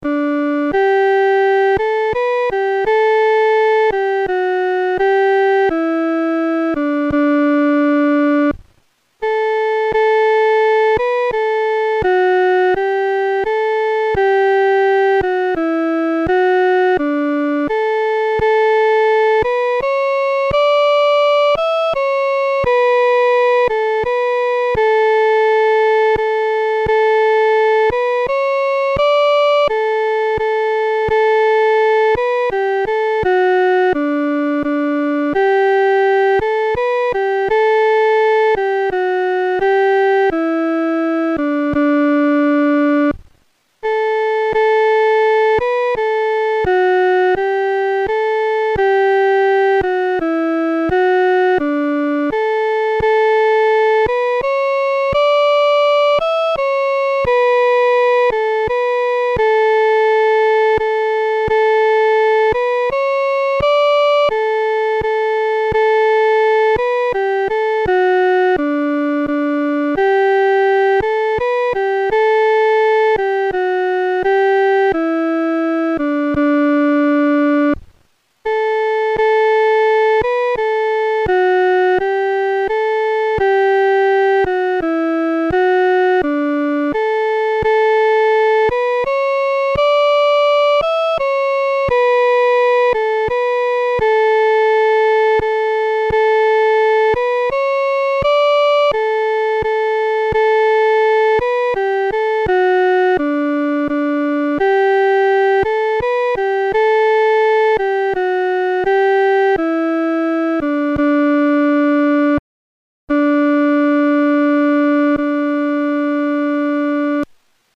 合唱
女高